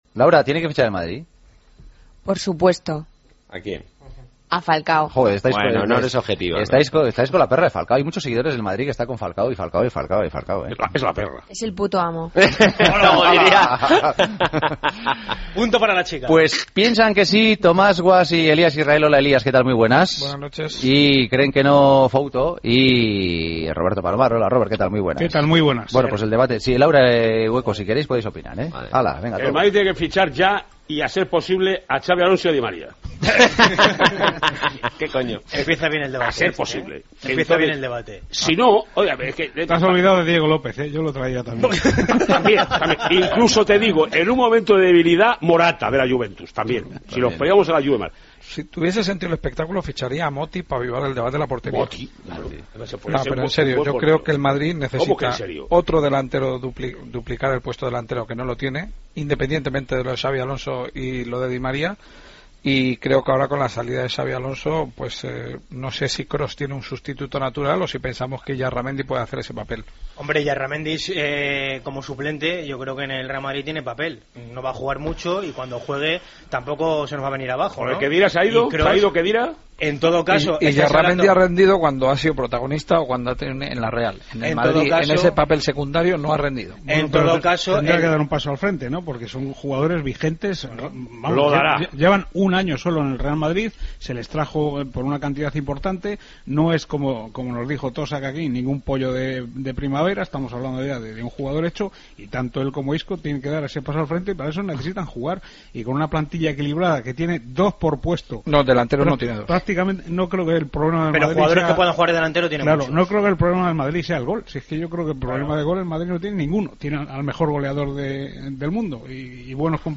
El debate de los jueves: ¿Debe fichar el Real Madrid?